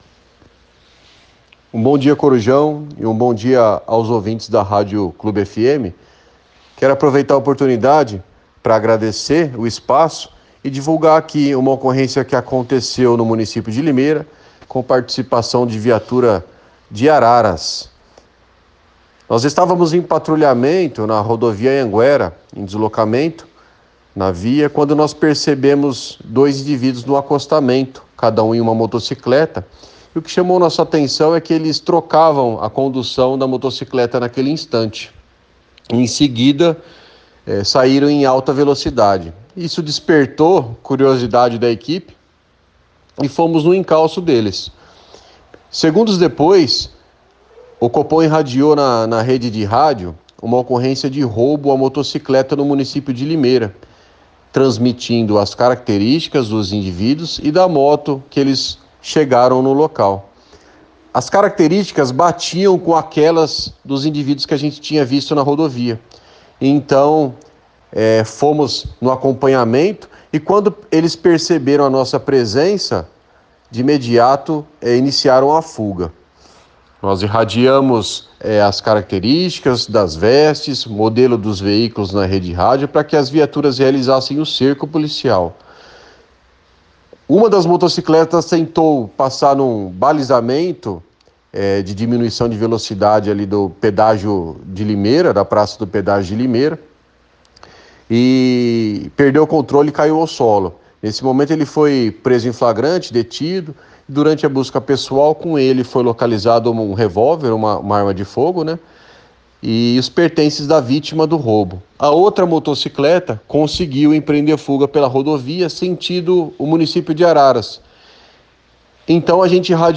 conversou com exclusividade